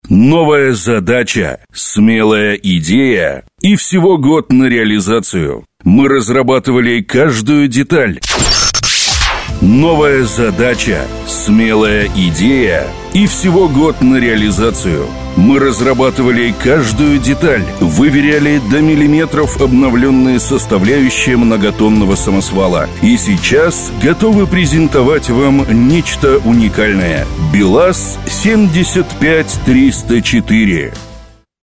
Сейчас работаю диктором в совершенно разных манерах, стилях, подачах.
Микрофон AKG P120, интерфейс USB Dual Tube Pro(ламповый), дикторская кабина.